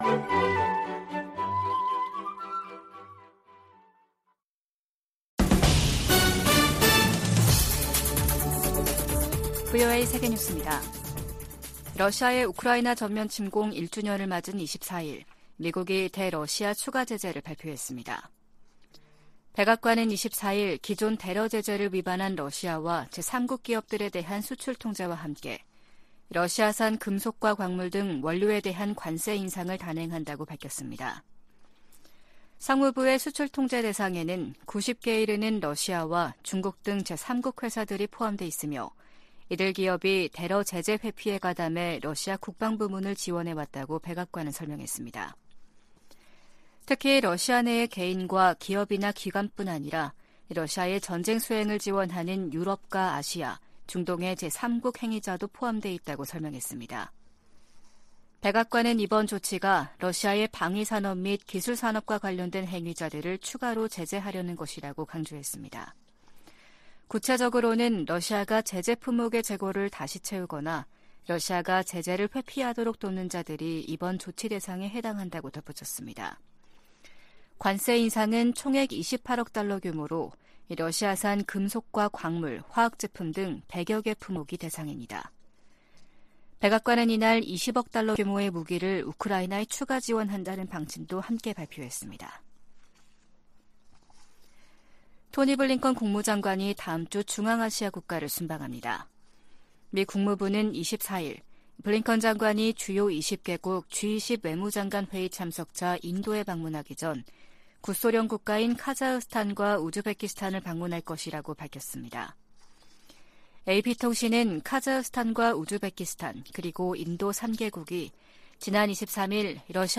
VOA 한국어 아침 뉴스 프로그램 '워싱턴 뉴스 광장' 2023년 2월 25일 방송입니다. 북한은 미국이 연합훈련 등 적대적이며 도발적인 관행을 계속 이어가면 선전포고로 간주될 수 있다고 밝혔습니다. 미 국방부는 미한 두 나라가 22일 펜타곤에서 제8차 양국 확장억제 운용연습(DSC TTX)을 실시했다고 밝혔습니다. 미 민주당의 브래드 셔먼 하원의원이 한국전쟁 종전선언을 비롯한 ‘한반도 평화’ 조치를 담은 법안을 다음 주 재발의할 예정입니다.